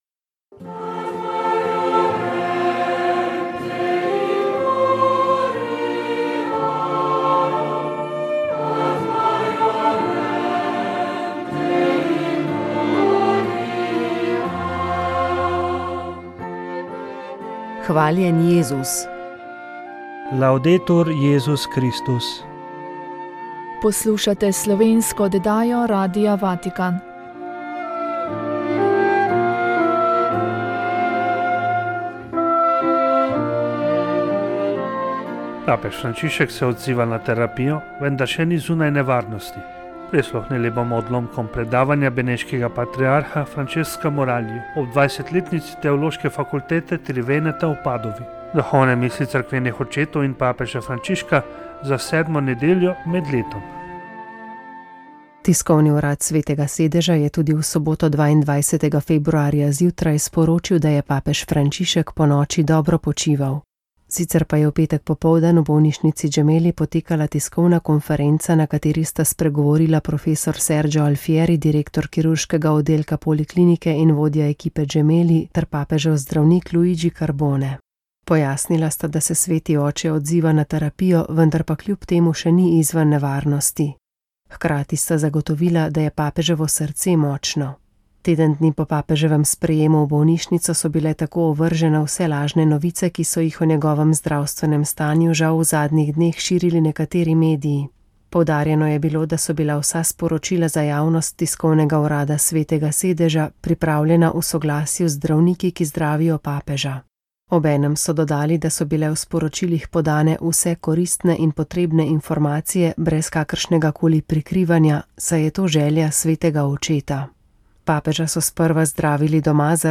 Molili so člani Karitas iz župnij Preska in Smlednik.